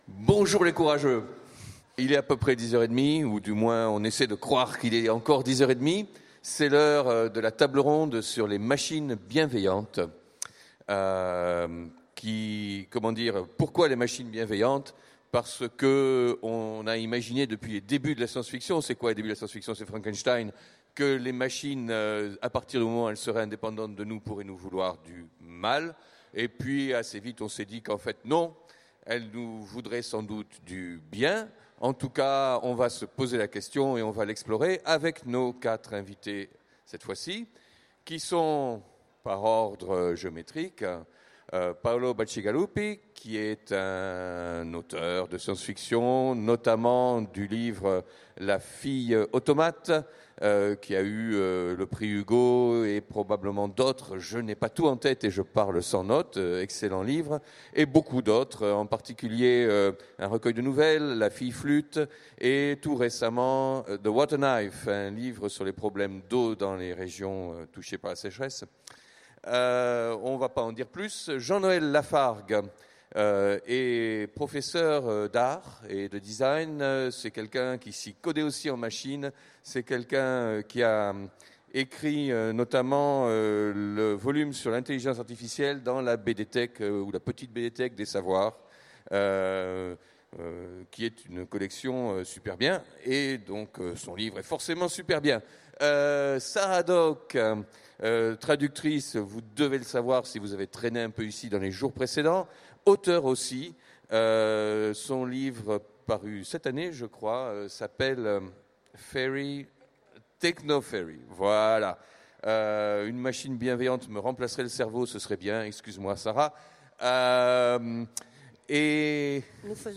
Utopiales 2016 : Conférence Bienveillantes machines de la science-fiction